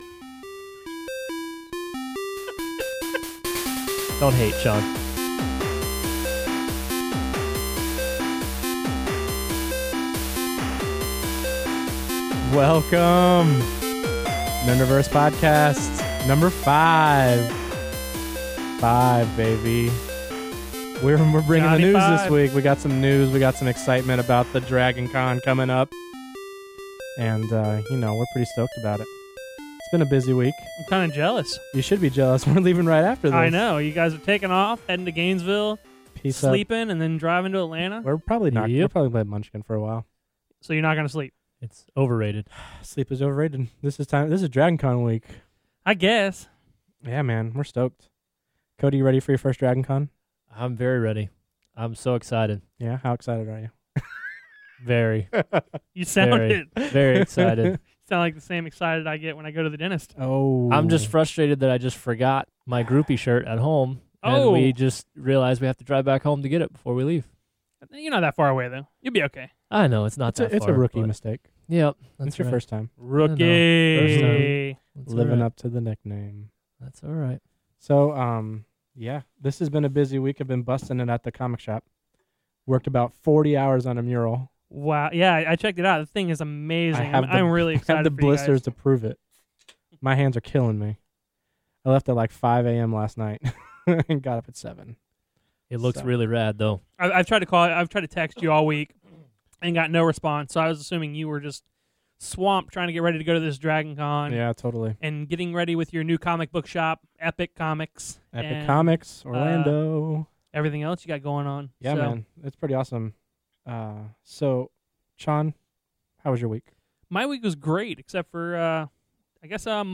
Also a guest caller and our first week of Trek Talk… ENJOY!